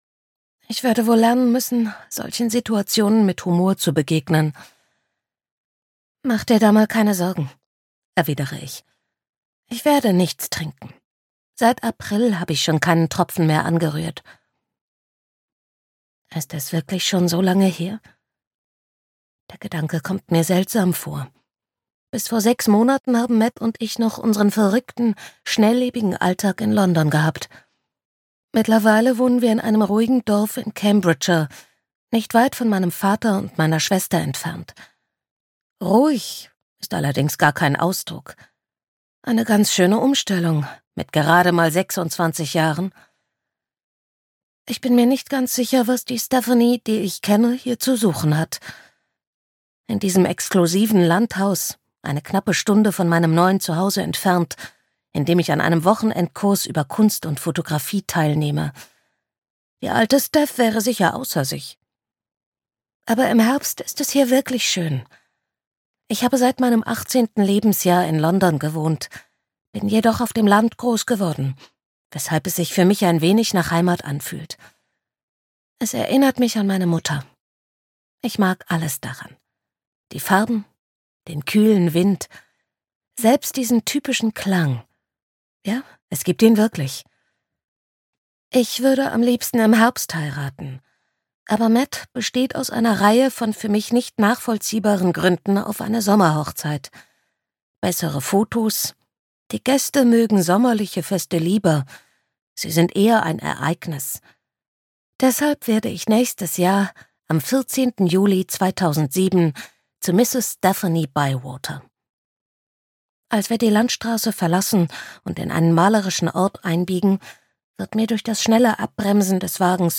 Kein Jahr ohne dich - Roxie Cooper - Hörbuch